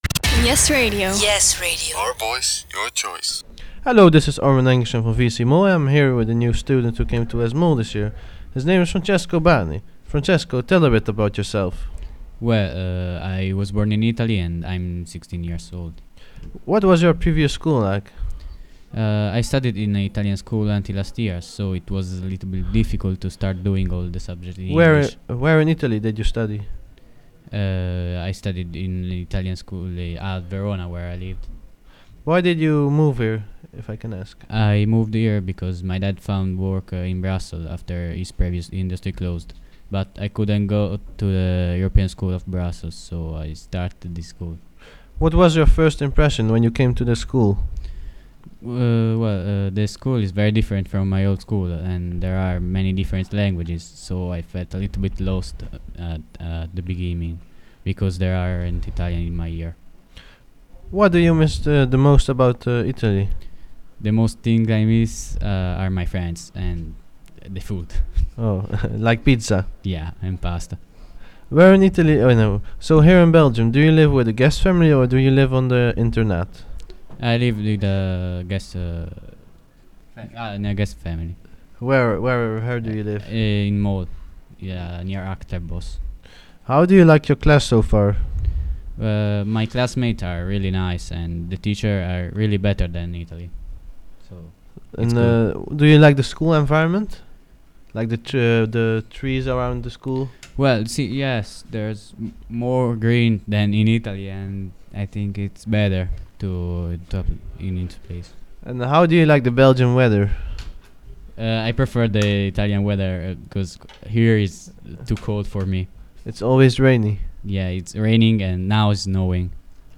Intervista a New Student Interview